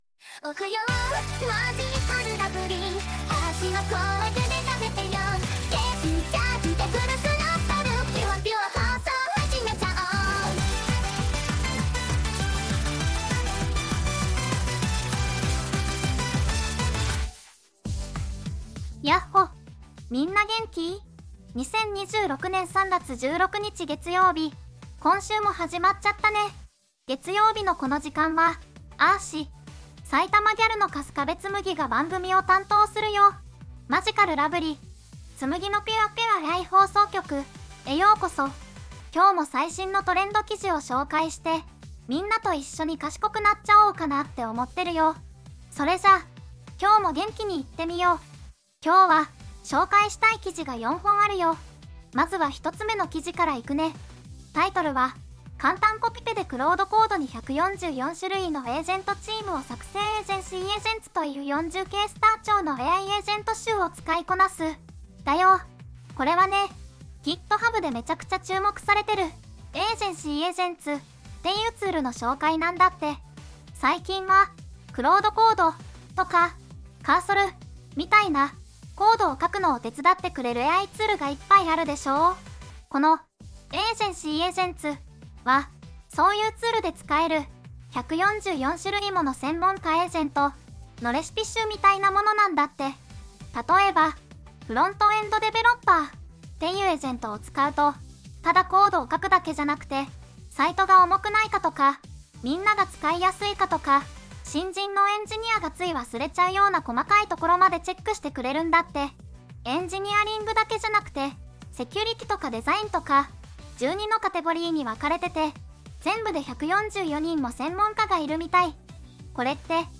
VOICEVOX:春日部つむぎ